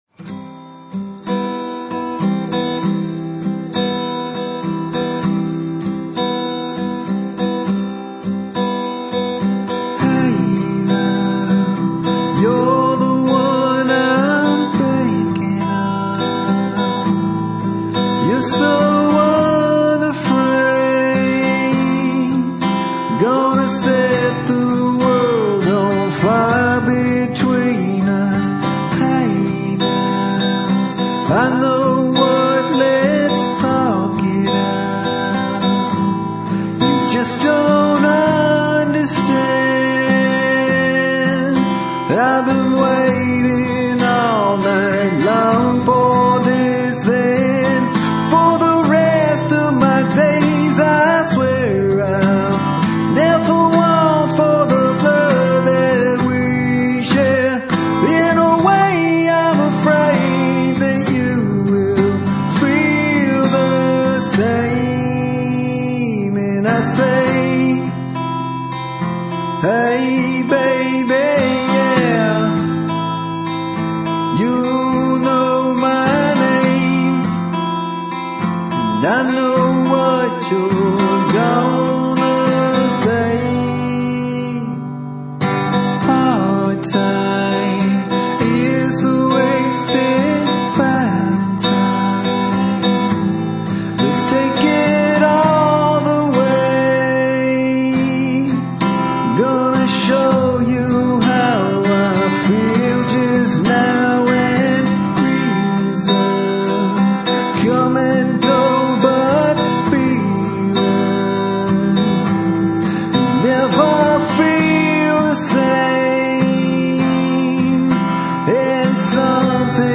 rock
punk
metal
high energy rock and roll